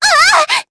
Dosarta-Vox_Damage_jp_02.wav